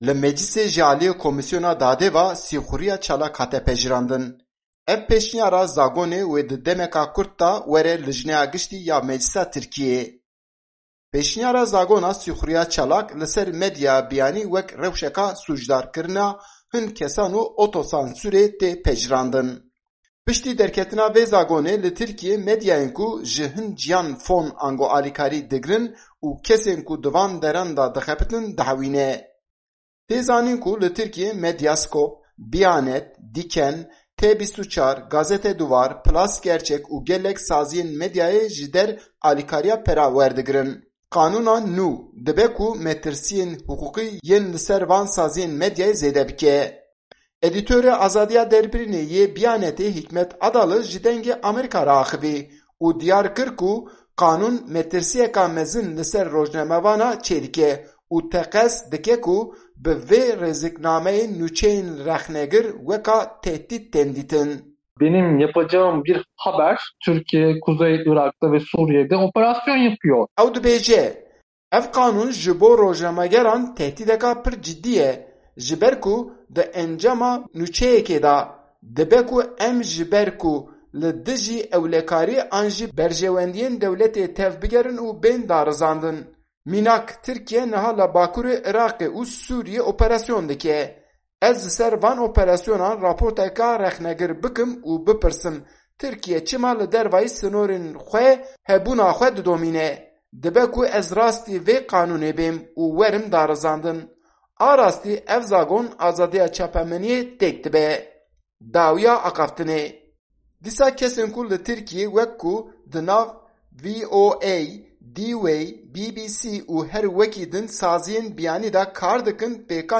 Raporta Enqerê - Zagona Sîxurîya Çalak